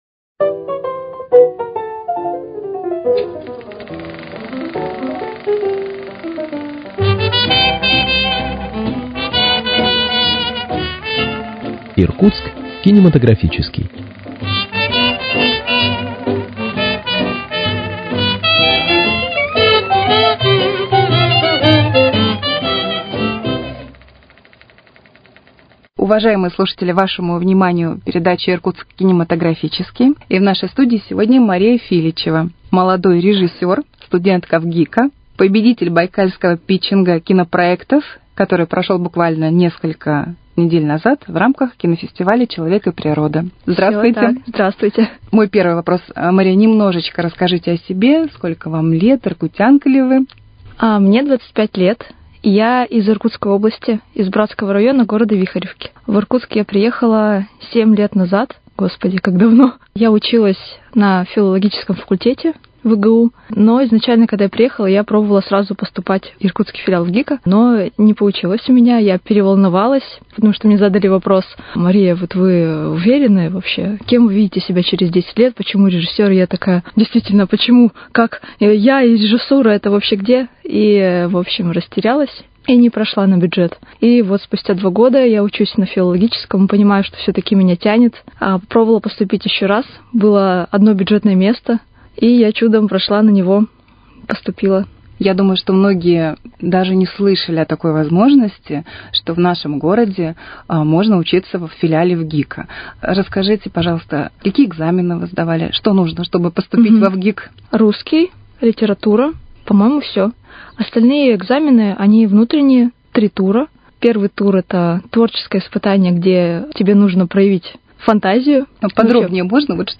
Иркутск кинематографический: Беседа с молодым режиссером